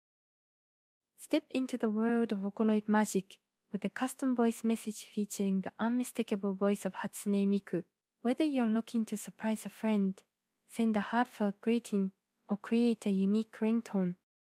Custom Voice Message with Hatsune Miku Voice
• Authenticity: Our technology faithfully reproduces Hatsune Miku’s signature voice, staying true to the Vocaloid experience.